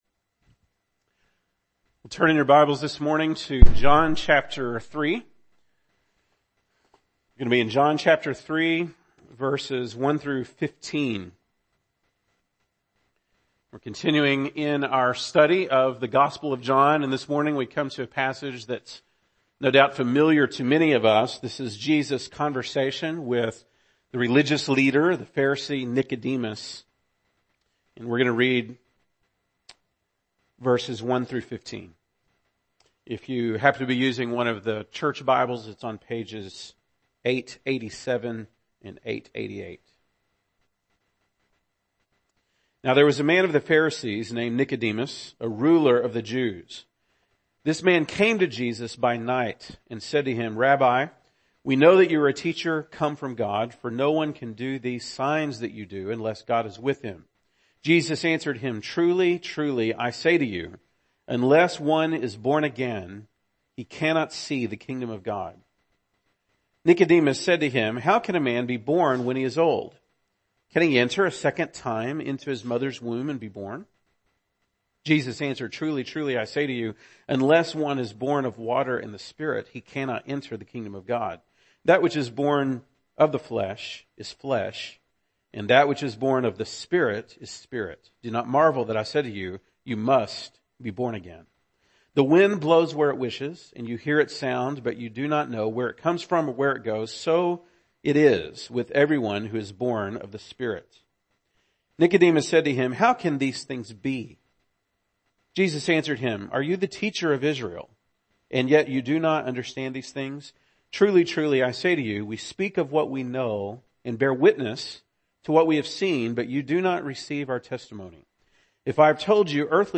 February 5, 2017 (Sunday Morning)